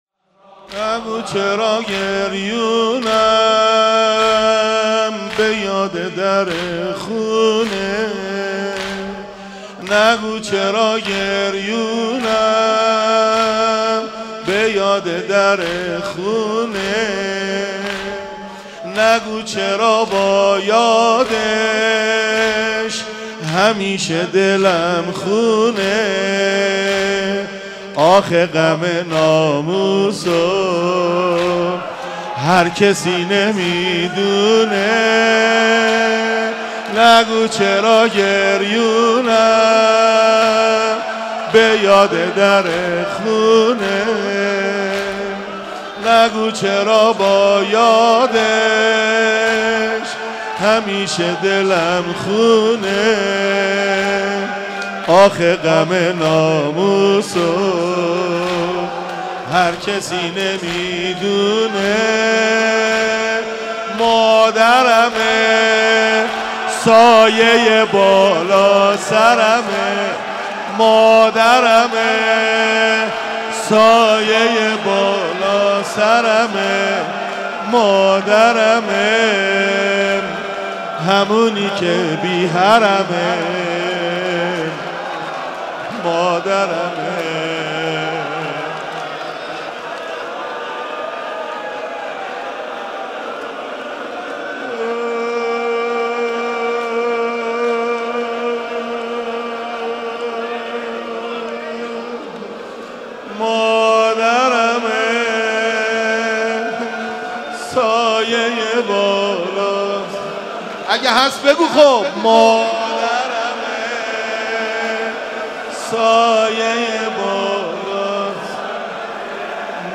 شب اول محرم الحرام 1394 | مسجد حضرت امیر
نگو چرا گریونم به یاد در خونه | زمینه | حضرت زهرا سلام الله علیها